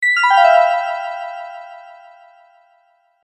メール音やSMSの通知音にぴったりな長さの音です。